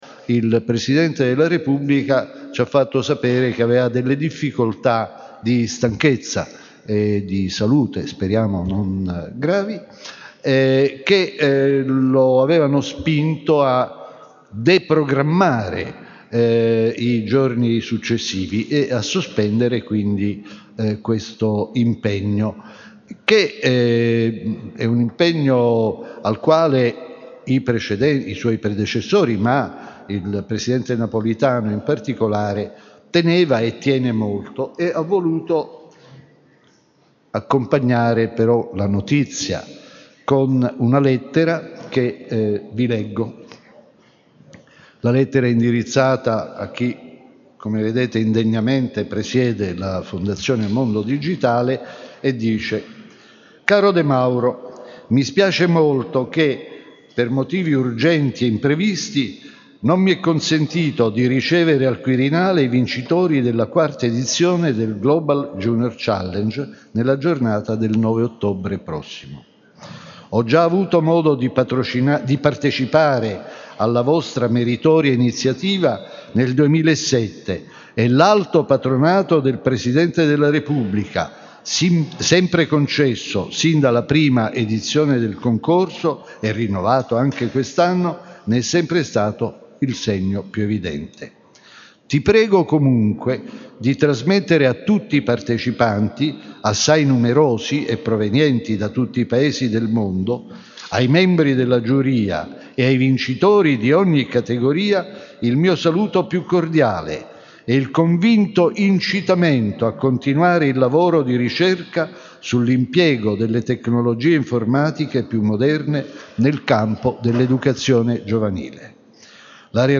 Il messaggio del Presidente della Repubblica
Messaggio_Napolitano.mp3